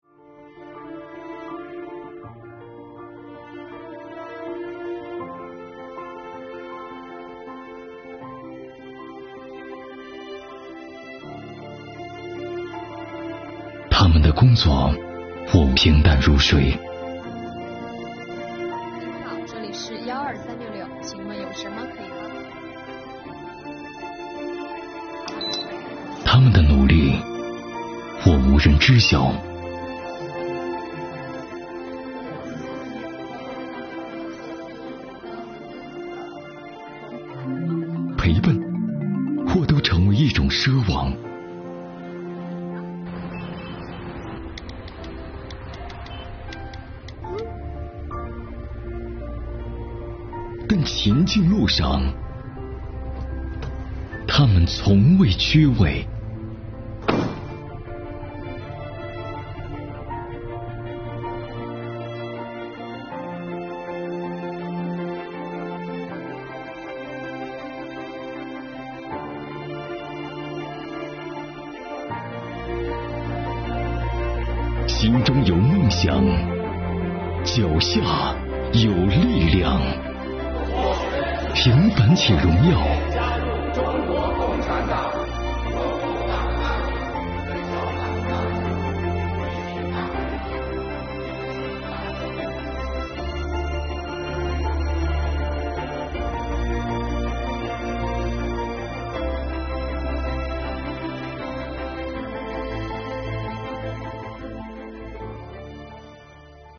公益广告丨平凡且荣耀